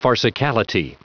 Prononciation du mot farcicality en anglais (fichier audio)
Prononciation du mot : farcicality